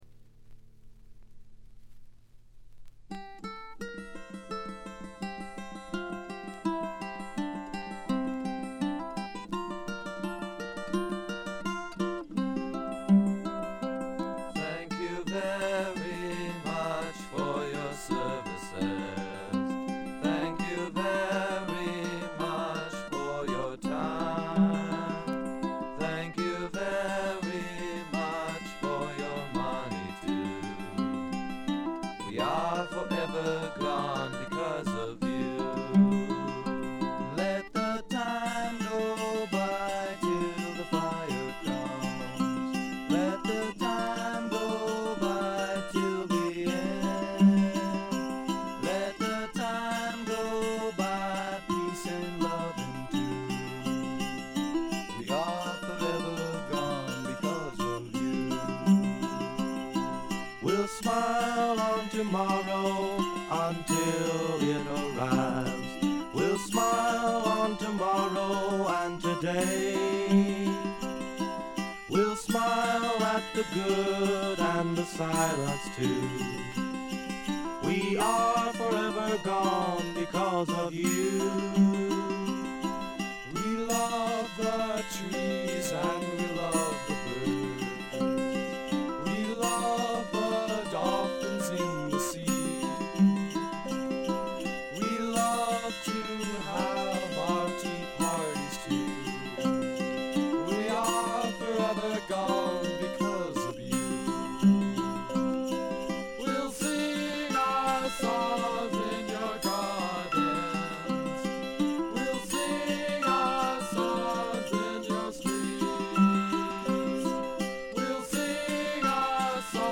部分試聴ですが静音部での軽微なチリプチ程度。
フォーク、ロック、ポップをプログレ感覚でやっつけたというか、フェイクで固めたような感覚とでもいいましょうか。
試聴曲は現品からの取り込み音源です。